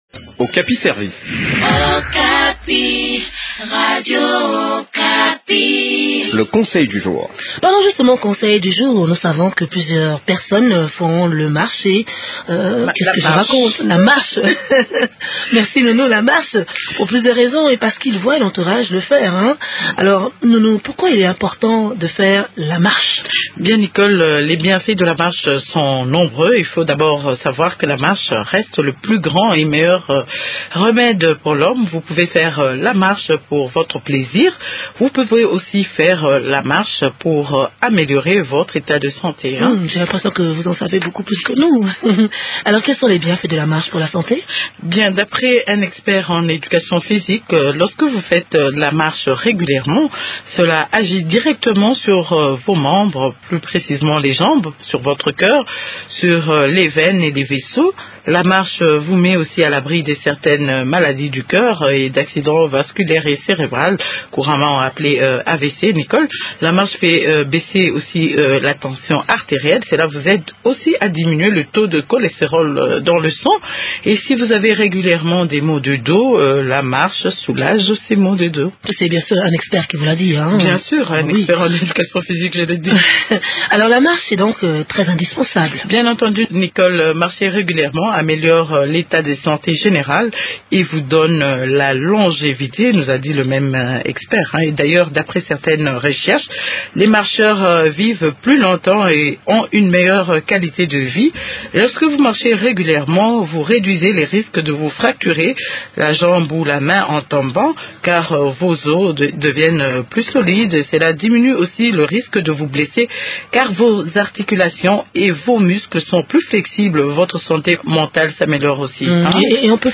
Découvrez les bienfaits de la marche à pieds dans ce compte rendu